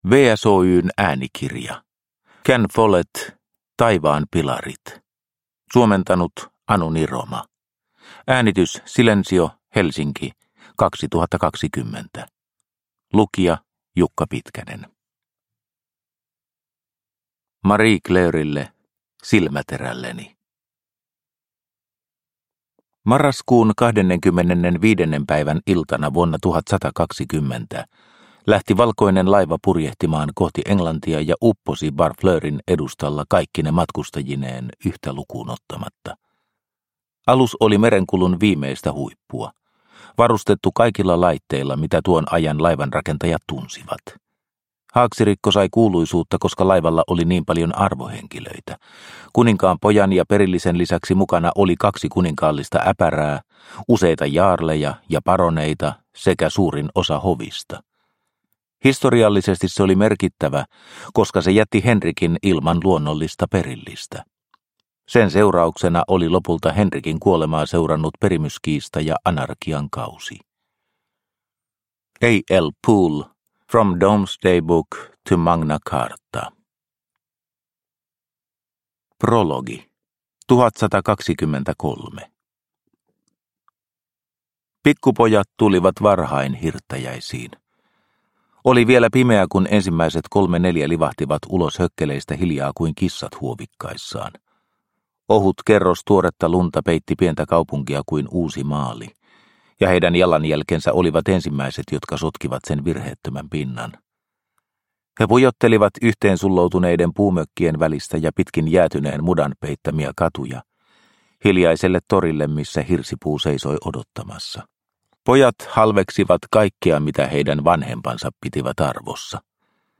Taivaan pilarit – Ljudbok – Laddas ner